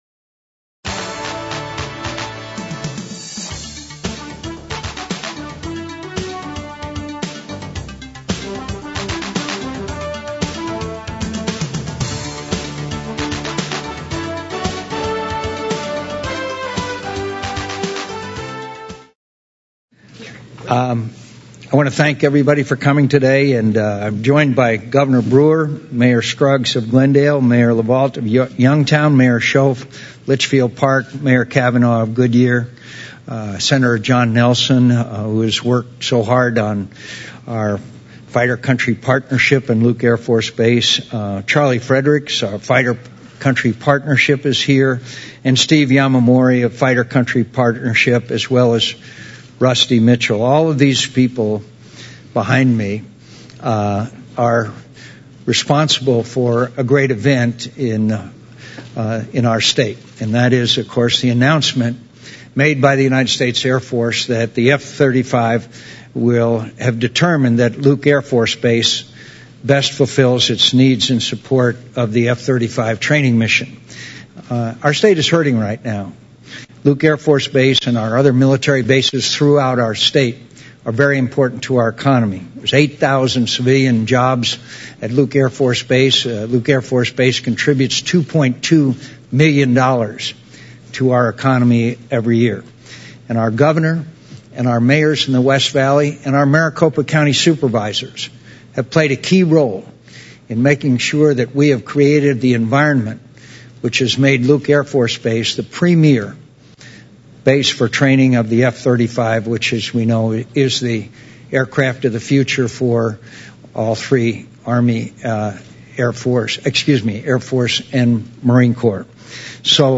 SENATOR McCAIN, GOVERNOR BREWER HELD PRESS CONFERENCE
Held at: Glendale Regional Public Safety Training Center
U.S. Senator John McCain (R-AZ), Arizona Governor Jan Brewer (R), and local elected officials held a press conference TODAY, Friday, July 30, 2010 at 12:30 p.m. PT in Glendale, Arizona to discuss the Air Force decision to station the F-35 Lightning II at Luke Air Force Base.